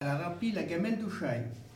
Saint-Hilaire-de-Riez
Catégorie Locution